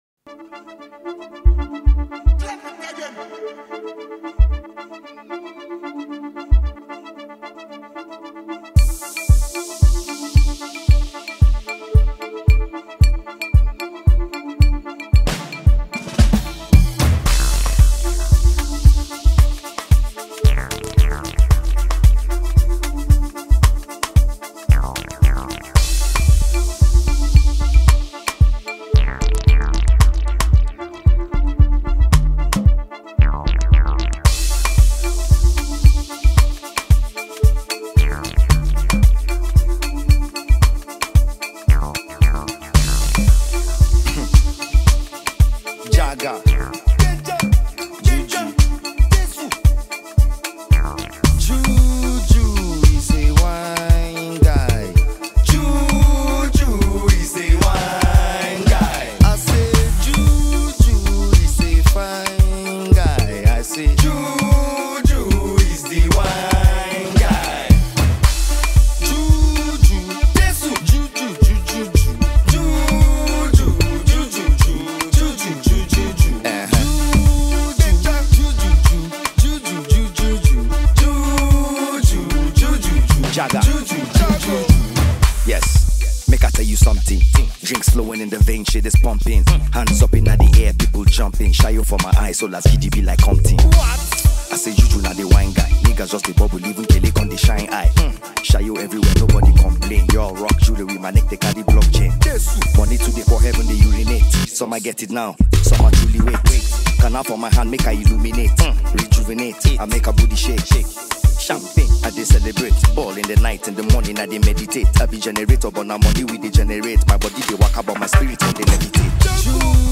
Well-known Nigeria Rapper and songwriter
one of the finest rapper from North Nigeria.